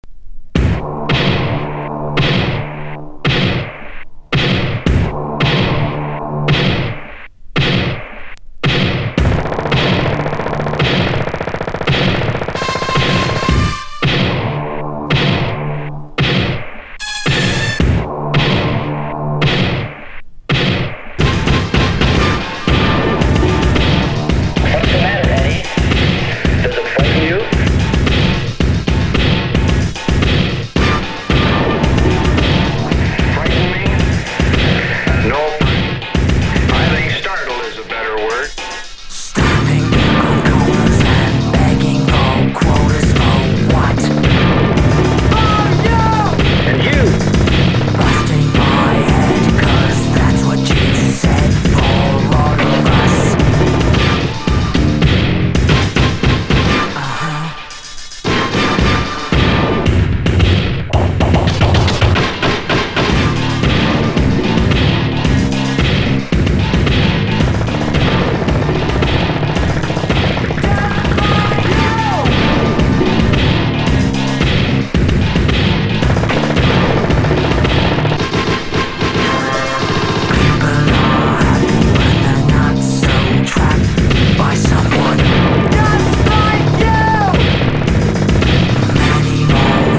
1. 90'S ROCK >